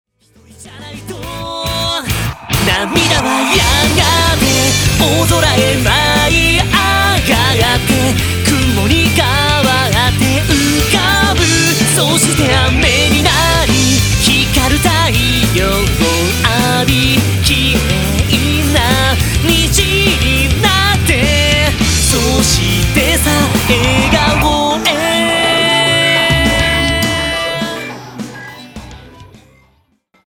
アイドルソング